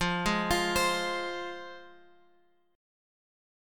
Fm chord {x 8 6 x 6 8} chord
F-Minor-F-x,8,6,x,6,8-8.m4a